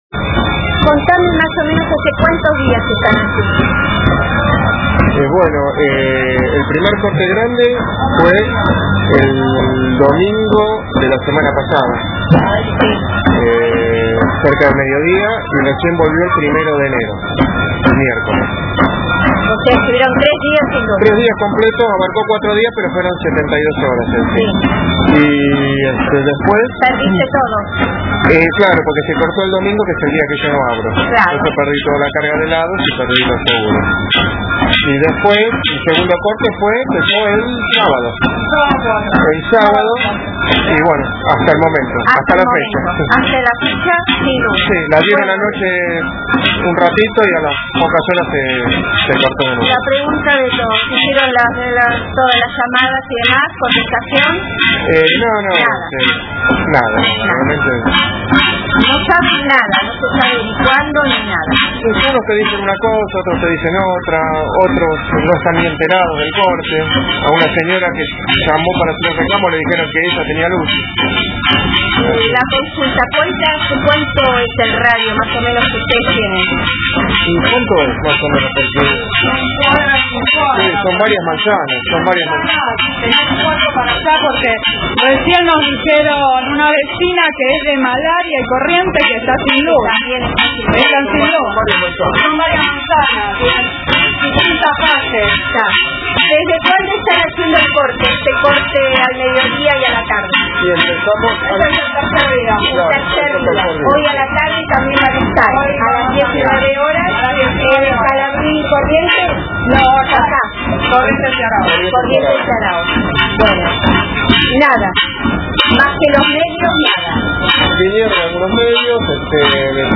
A una cuadra, en Aráoz y Corrientes por tercer día consecutivo los vecinos están cortando esta arteria reclamando por la falta de luz, agua y tolerando la negación sistemática de la realidad.